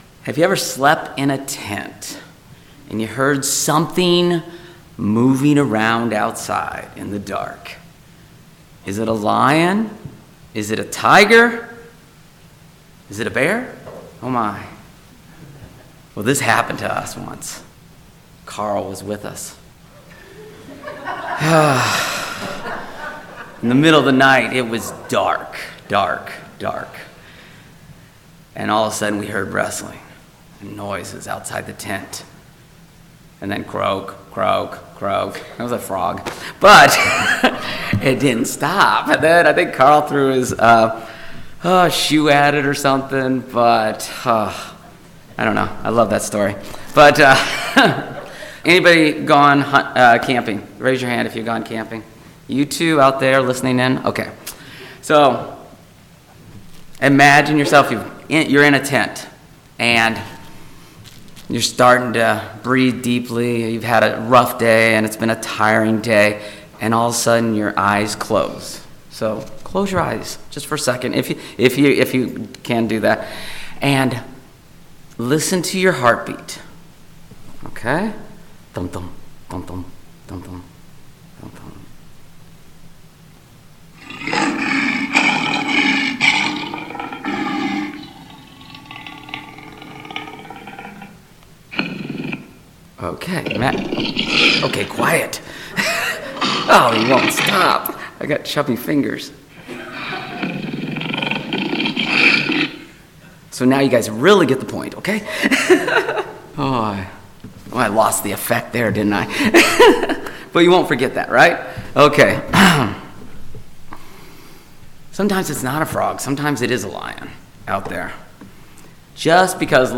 This sermon teaches some good and bad spiritual lessons from lions in the way they hunt.